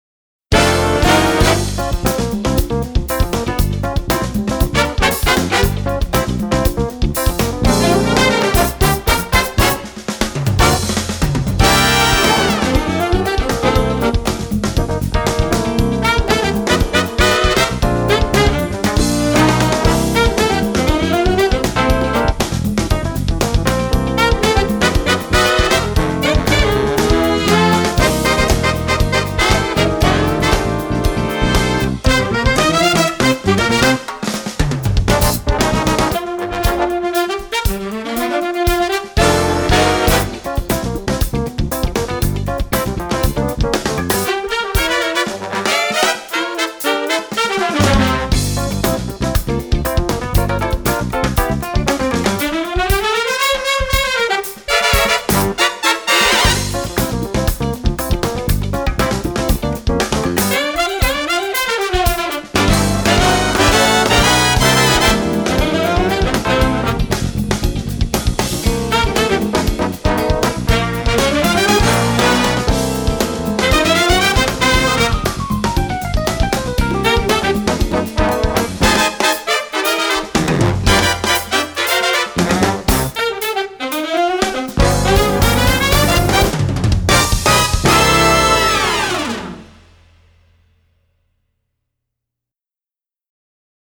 funk, jazz